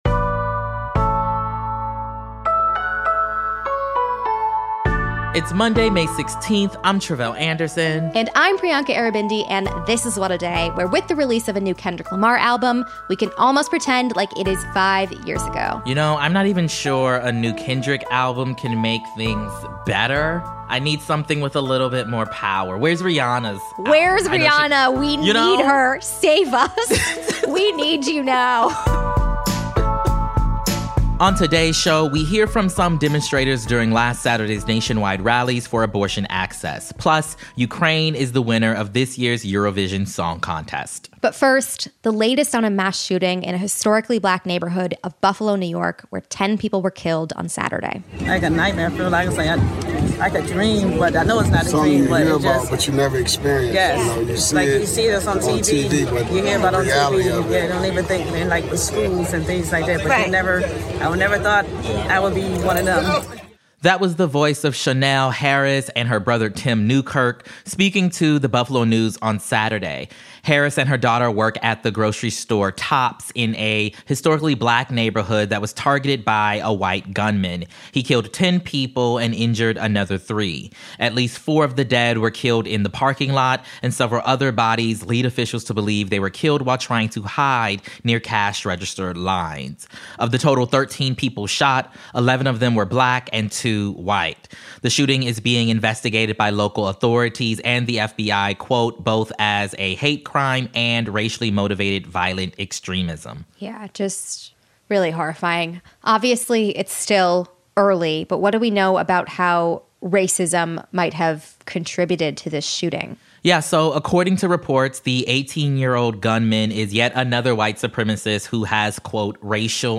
More than 450 “Bans Off Our Bodies” marches happened around the country, and we hear from demonstrators who showed up in Los Angeles to support abortion rights.